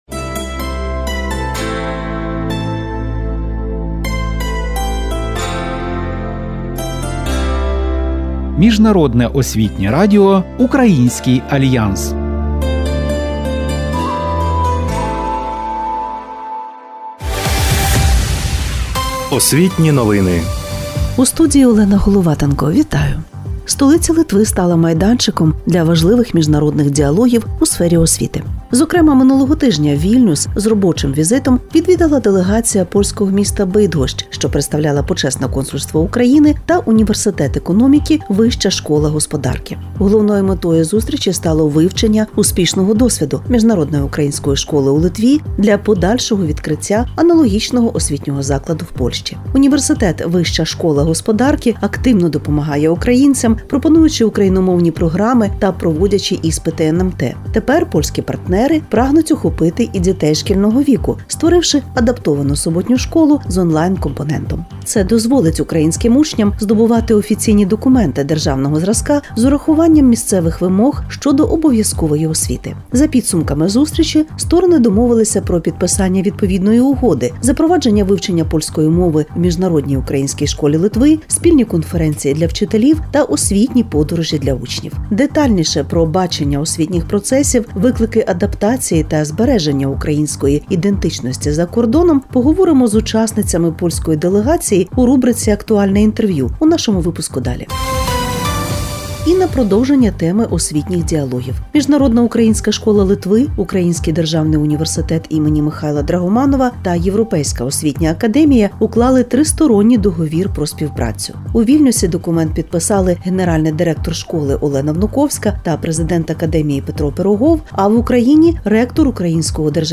У програмі: візит польської делегації з Бидгоща до Вільнюса, нові партнерські угоди МУШ Литви з українськими університетами, актуальне інтерв'ю про перспективи української освіти в Польщі, життєвий шлях Пауля Целана, аудіоурок про литовські прикметники, календар НМТ-2026 та екологічні виклики Амазонії.